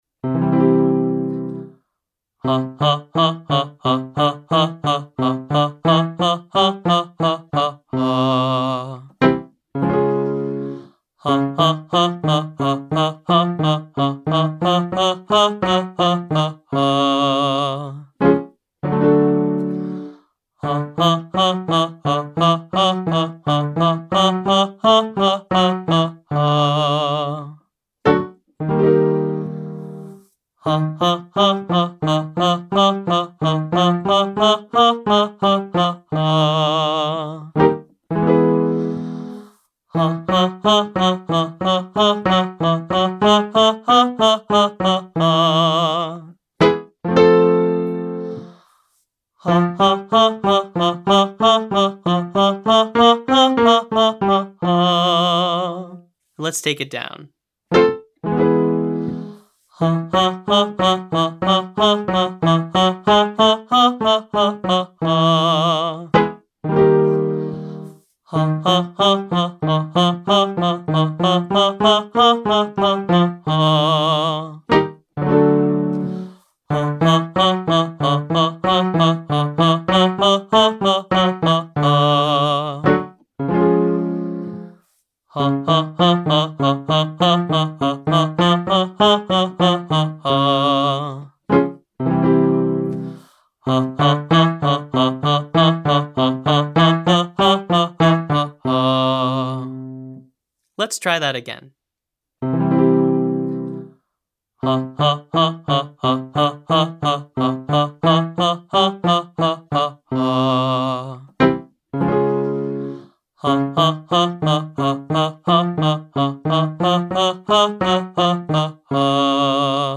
Staccato Exercises
Staccato involves singing short, detached notes, which is the opposite of smooth, connected legato singing.
1. Diatonic Staccato Exercise (Zayah):
Vocal Agility Daily Warmup for Low Voice-2A